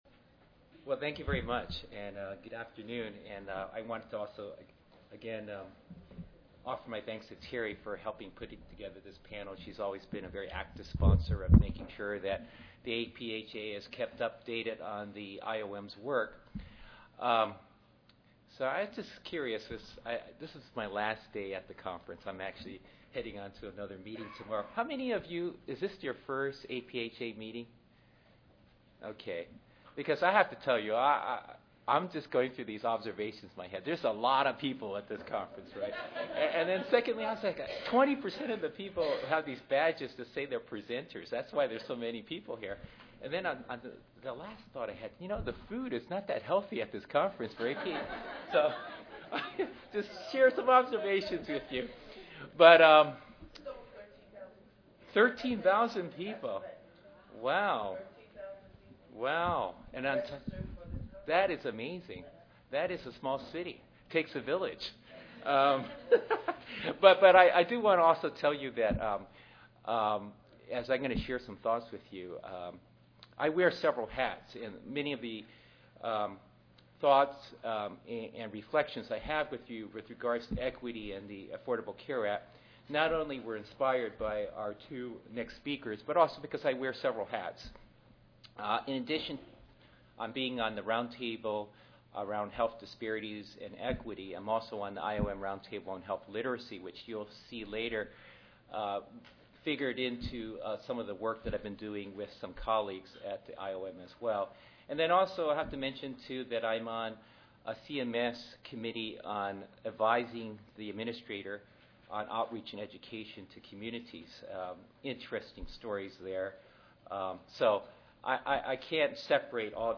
141st APHA Annual Meeting and Exposition (November 2 - November 6, 2013): What Has the IOM Roundtable Learned About Community Engagement and Empowerment?